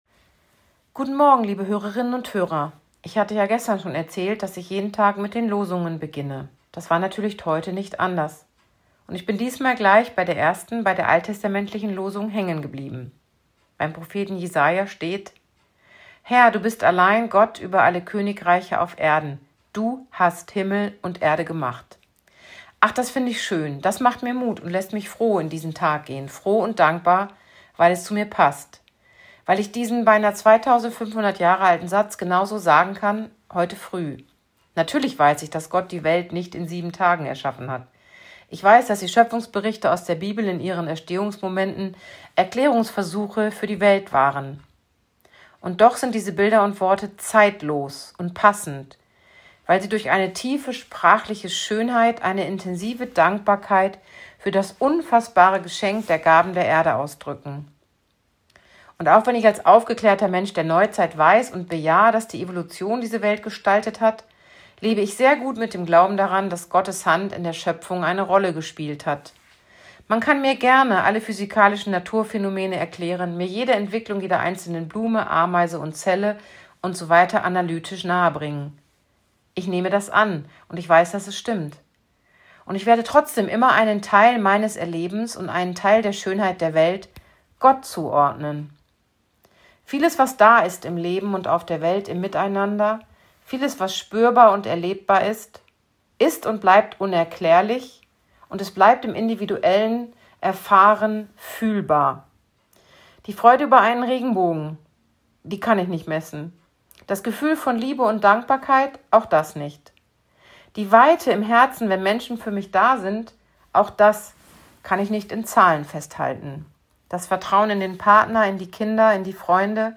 Radioandacht vom 29. August